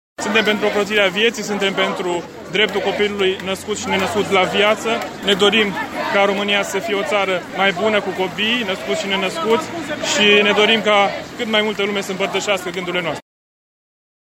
organizator: